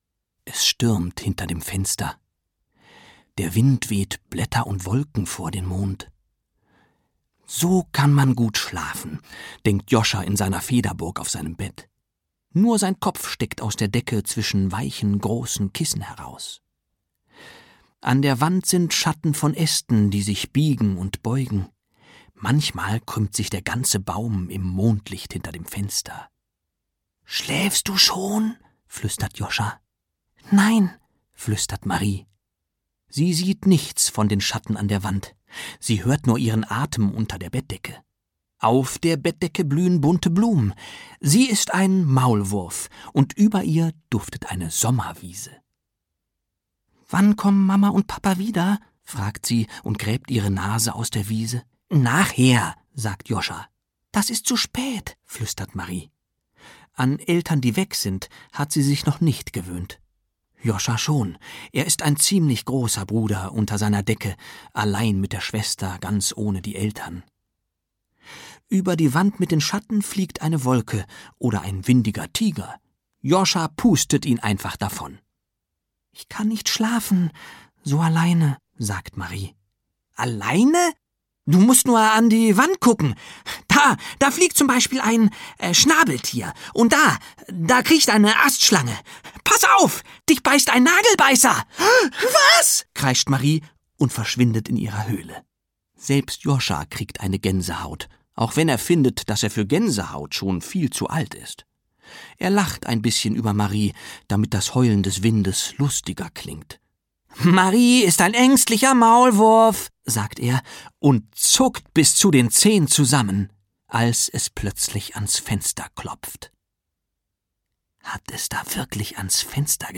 Wir sind nachher wieder da, wir müssen kurz nach Afrika - Autorenlesung
Hörbuch